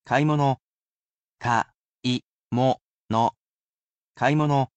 I am sure to read each of the words aloud for you, slowly and carefully.